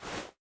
Player_Crouch 02.wav